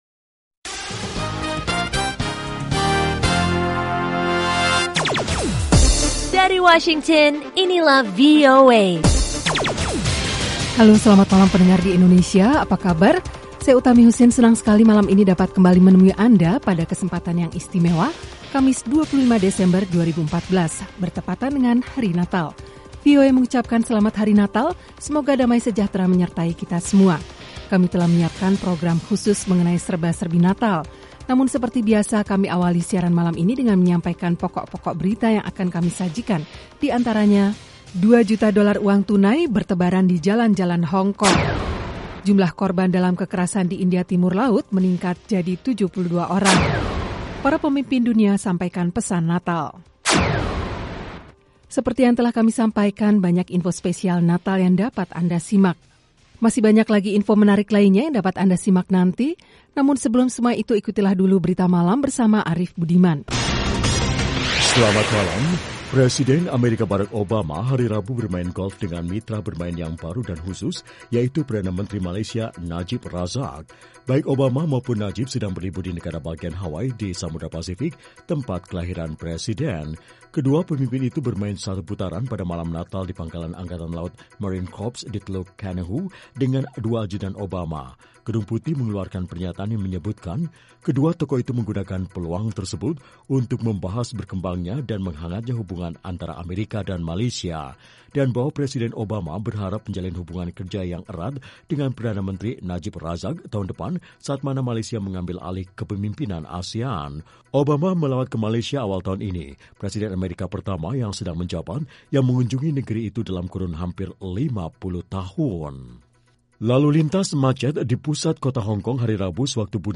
Selain padat dengan informasi, program ini sepanjang minggu menyuguhkan acara yang bernuansa interaktif dan penuh hiburan.
Kami menyajikan berbagai liputan termasuk mengenai politik, ekonomi, pendidikan, sains dan teknologi, Islam dan seputar Amerika. Ada pula acara musik lewat suguhan Top Hits, music jazz dan country.